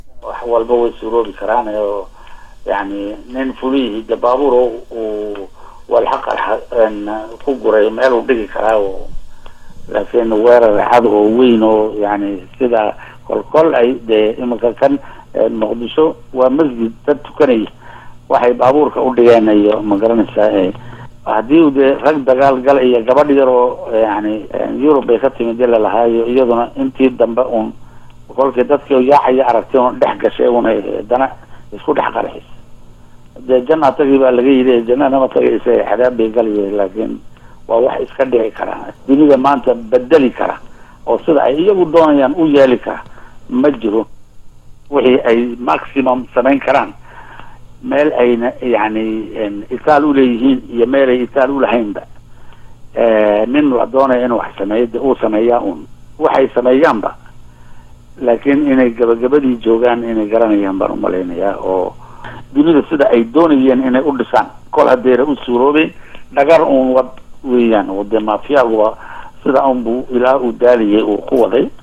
Madaxweynaha Jamhuuriyada Jabuuti Cismaaciil Cumar Geelle oo la hadlay Laanta Afka Soomaaligaa ee VOA ayaa ka hadlay Hadal hanjabaad ah oo Al-shabaab ay u direen Dalalka Mareykanka,Ingiriiska iyo Canadda oo ku Aadanaa in ay Weeraro ka geeysanayaan Wadamadaasi.